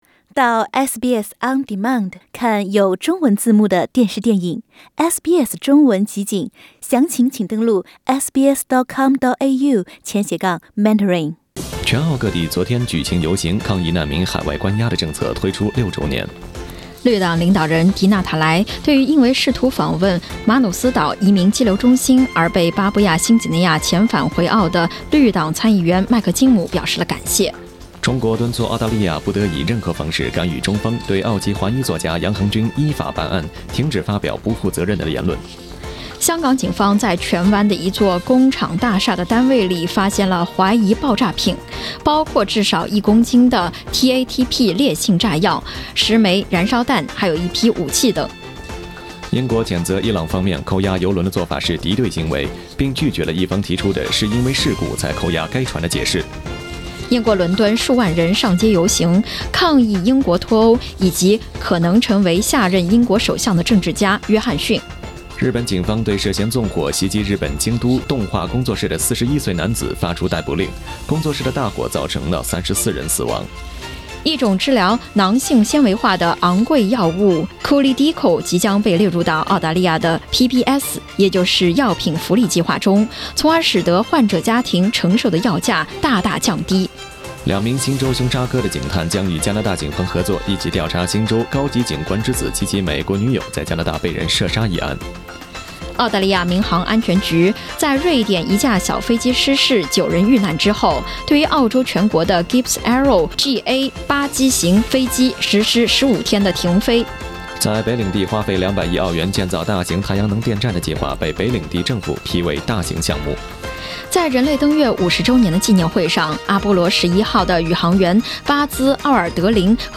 SBS早新闻 (7月21日）